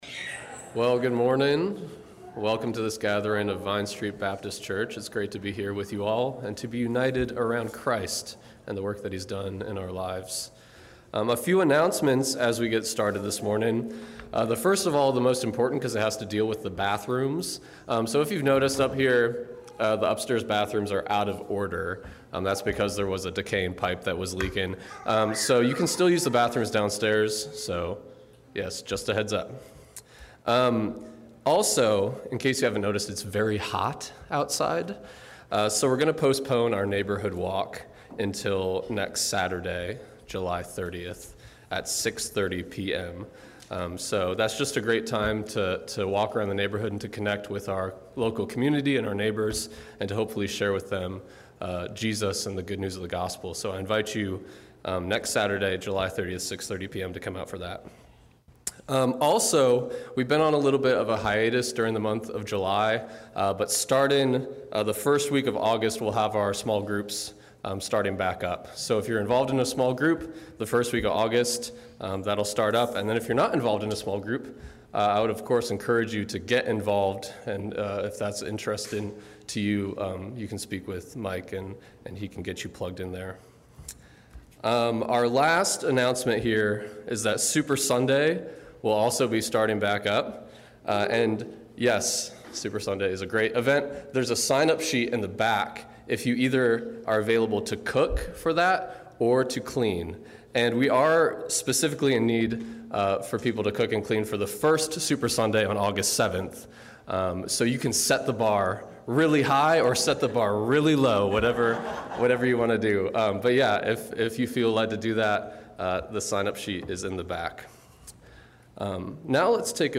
July 24 Worship Audio – Full Service